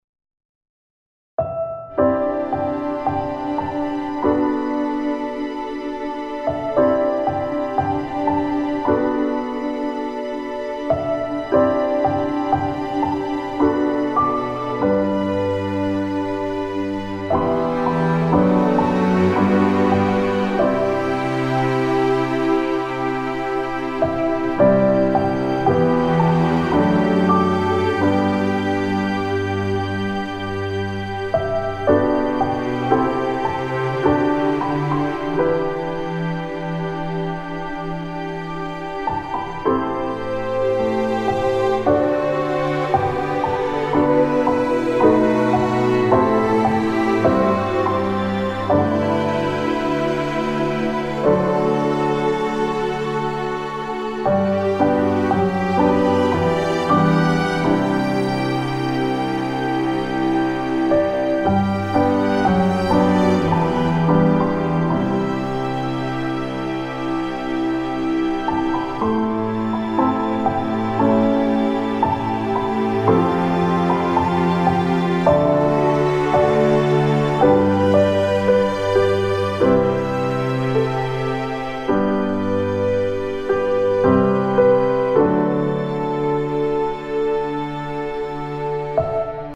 Disneystyle.mp3